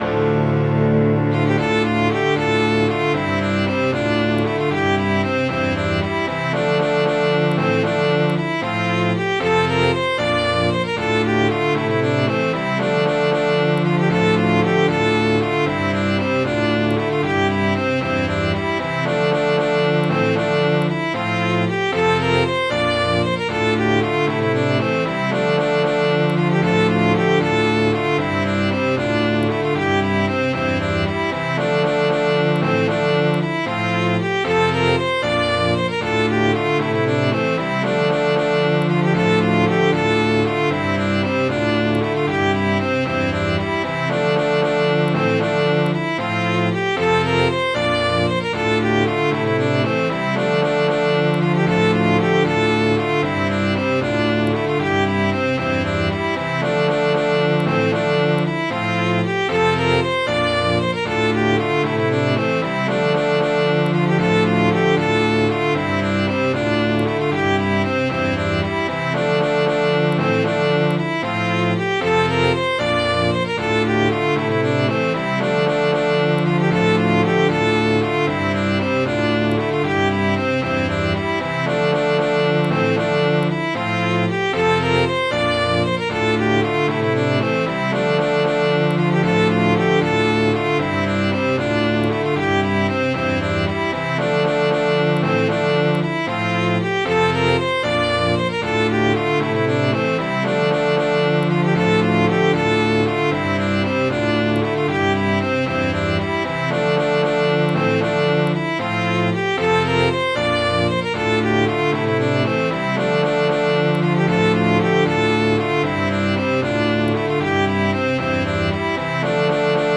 Midi File, Lyrics and Information to The Lincolnshire Farmer